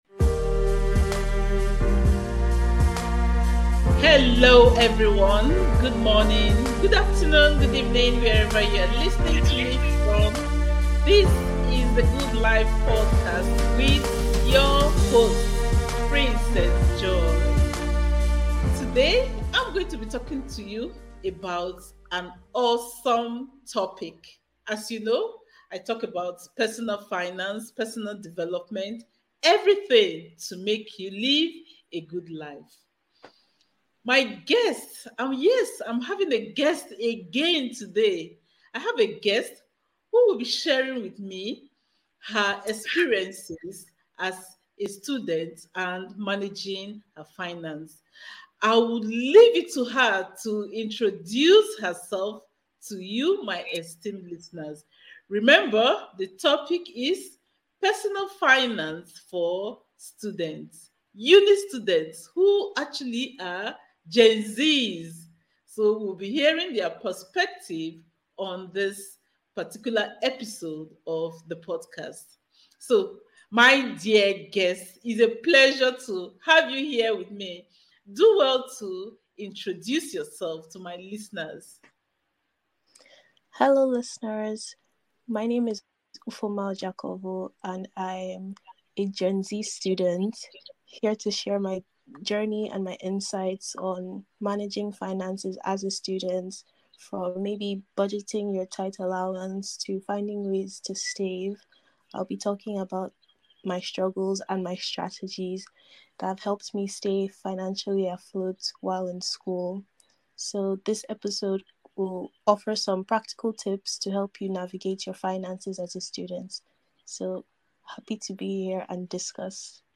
Stepping out of your comfort zone - Interview